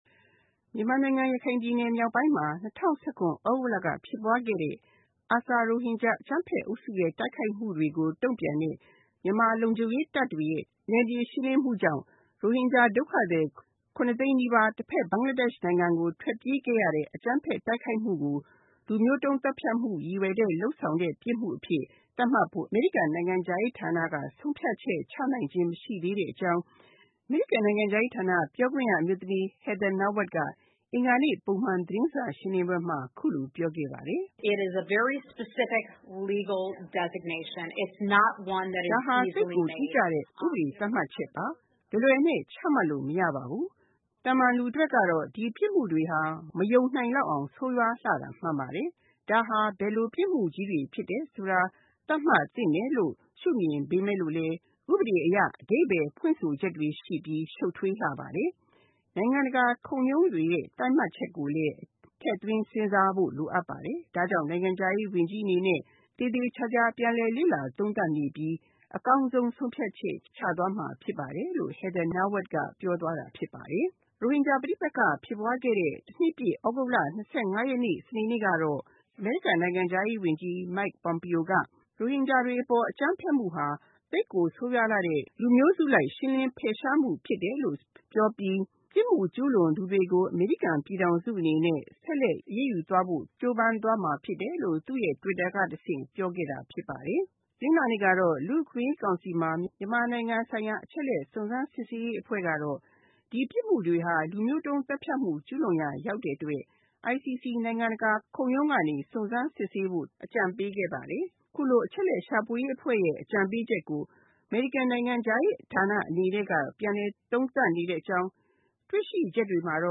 မြန်မာနိုင်ငံ ရခိုင်ပြည်နယ်မြောက်ပိုင်းမှာ ၂၀၁၇ သြဂုတ်လက ဖြစ်ပွားခဲ့တဲ့ ARSA ရိုဟင်ဂျာ အကြမ်းဖက်အုပ်စုရဲ့ တိုက်ခိုက်မှုတွေကိုတုံ့ပြန်တဲ့ မြန်မာလုံခြုံရေးတပ်တွေရဲ့ နယ်မြေရှင်းလင်းမှုကြောင့် ရိုဟင်ဂျာဒုက္ခသည် ၇ သိန်းနီးပါး တဖက် ဘင်္ဂလားဒေ့ရှ်နိုင်ငံကို ထွက်ပြေးခဲ့ရတဲ့ အကြမ်းဖက်တိုက်ခိုက်မှုကို လူမျိုးတုံးသတ်ဖြတ်မှုရည်ရွယ်တဲ့ ပြစ်မှုအဖြစ်သတ်မှတ်ဖို့ အမေရိကန်နိုင်ငံခြားရေးဌာနက ဆုံးဖြတ်ချက်ချနိုင်သေးတာ မရှိသေးတဲ့အကြောင်း အမေရိကန်နိုင်ငံခြားရေးဌာန ပြောခွင့်ရအမျိုးသမီး Heather Nauert က အင်္ဂါနေ့ ပုံမှန်သတင်းစာရှင်းလင်းပွဲမှာ အခုလိုပြောခဲ့ပါတယ်။